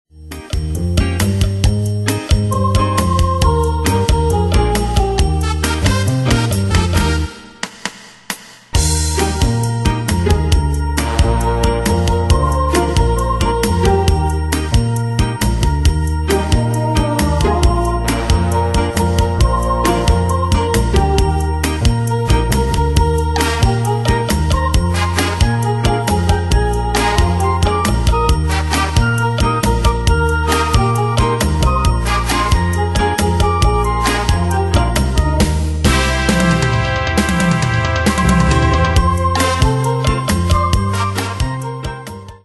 Style: Retro Année/Year: 1969 Tempo: 135 Durée/Time: 3.47
Danse/Dance: ChaCha Cat Id.
Pro Backing Tracks